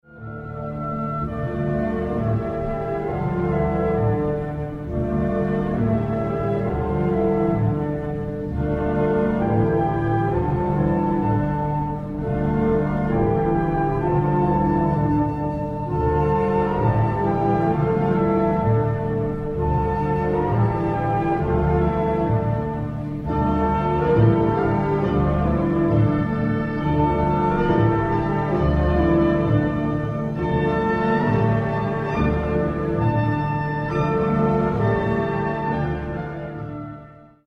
On proposera une écoute associée à cette chanson : il s’agit du début du 2ème mouvement de la 1ère Symphonie de Gustav Mahler dans lequel le compositeur reprend de la même manière le canon Frère Jacques en mode mineur.